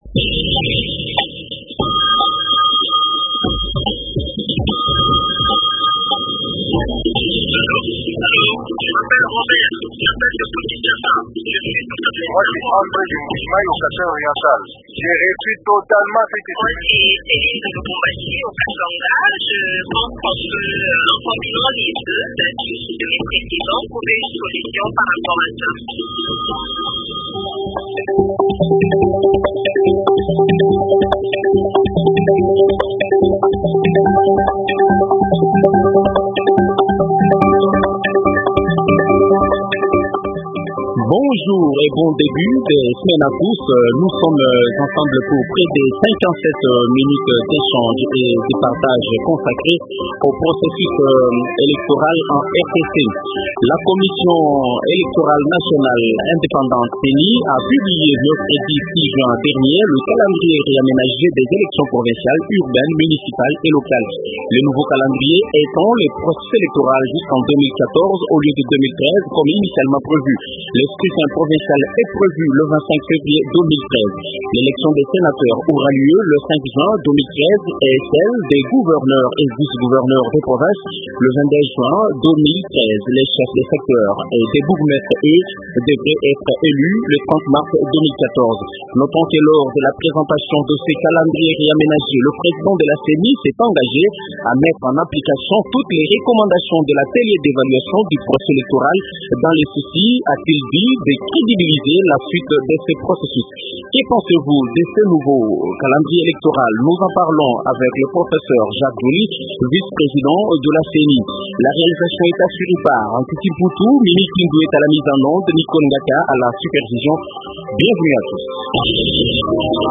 Invité : Professeur Jacques Djoli, vice président de la Ceni.